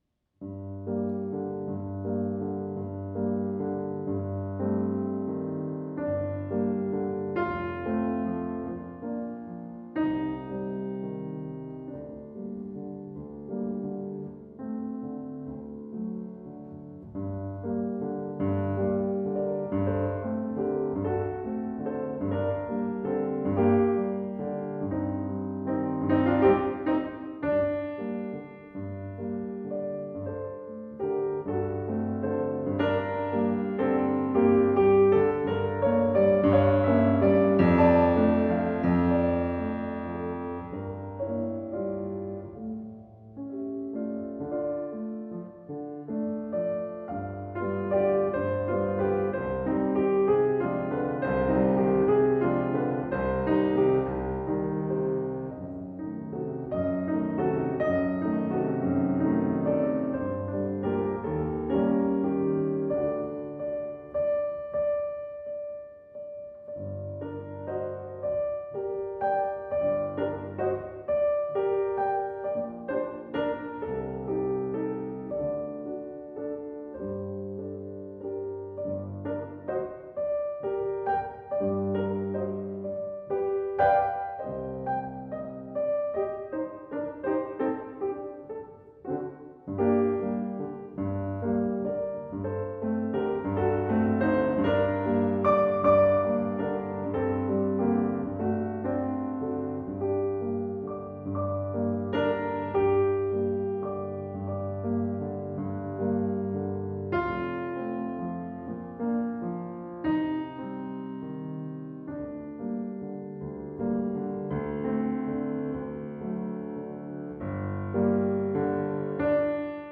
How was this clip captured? Recorded in a tractor barn in Colorado’s Vail Valley in 2006